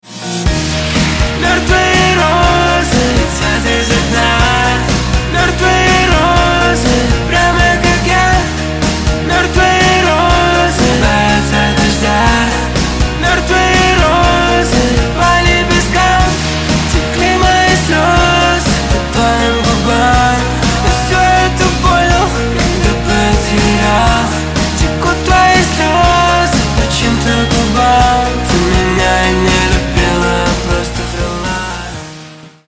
Русские Рингтоны » # Поп Рингтоны